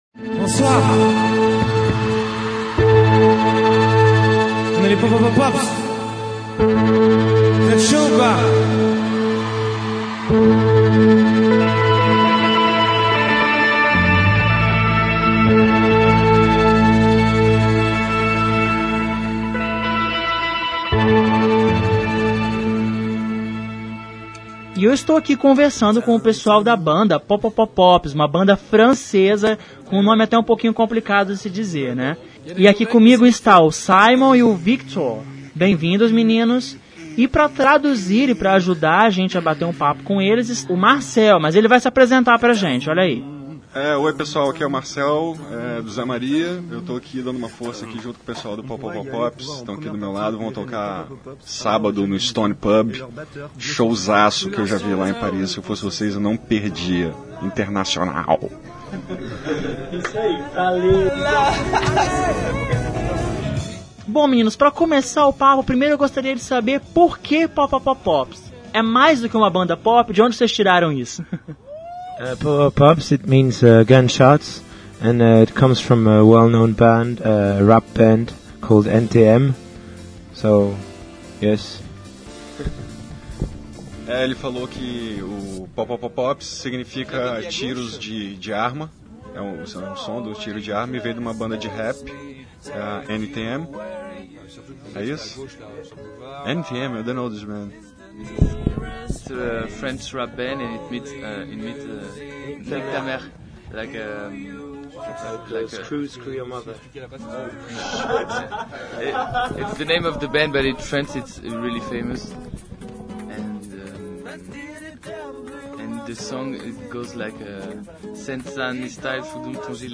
O Revista Universitária aproveitou o momento para bater um papo com os meninos da banda.